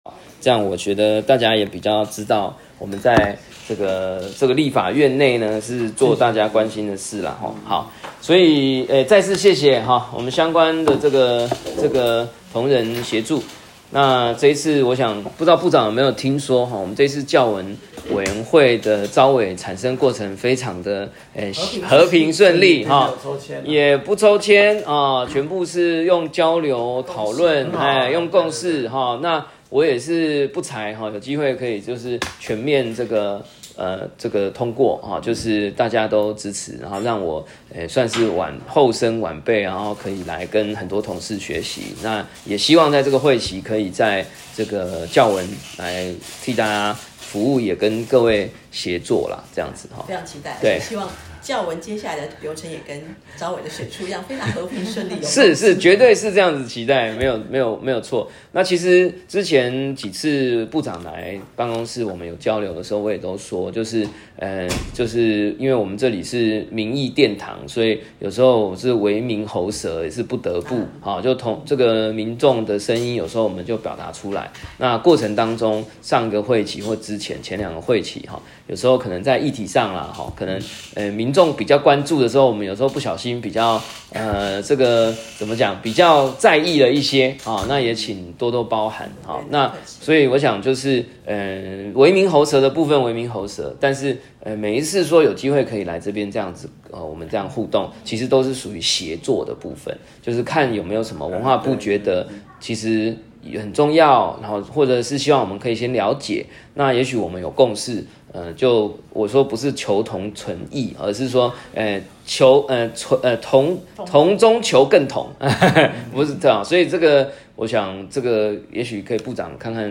時間：2025-03-07 與會人士：葛如鈞委員、文化部 - 01、文化部 - 02、葛如鈞委員辦公室